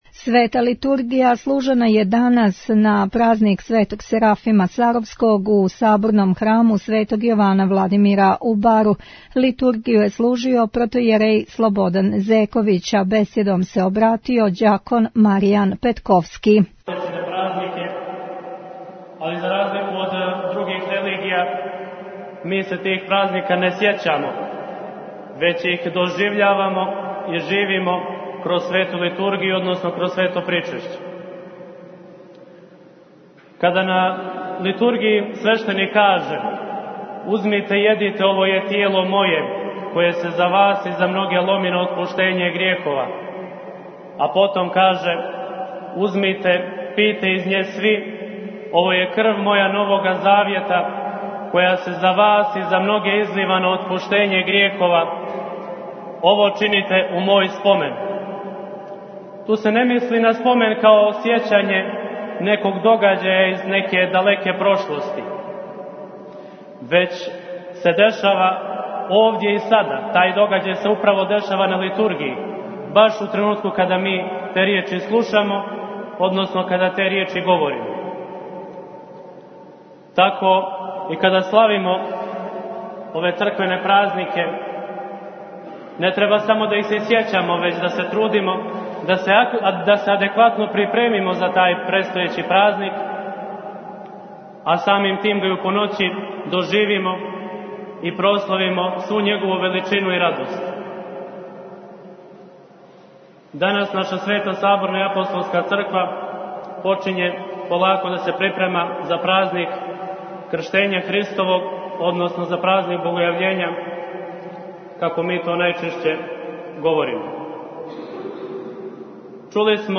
У Недјељу 30. по Духовима служена Литургија у Саборном храму Светог Јована Владимира у Бару
У прилогу доносимо тонски запис бесједе.